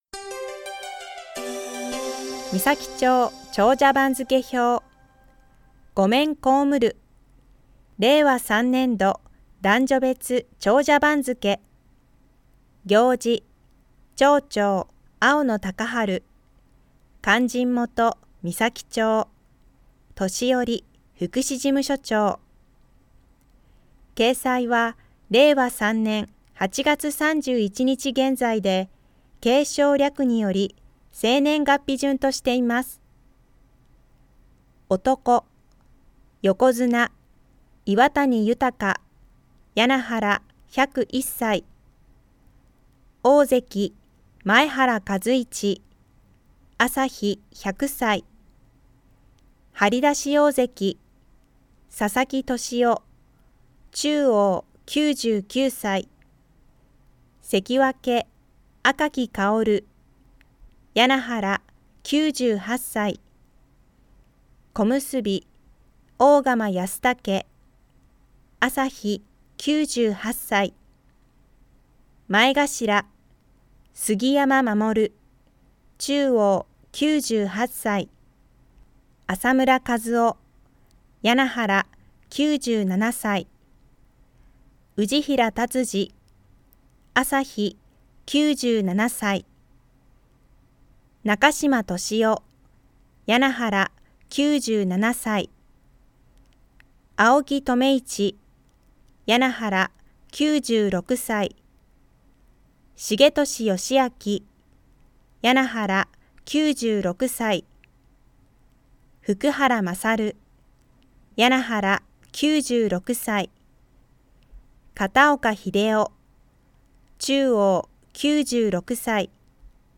広報誌の一部を読み上げています。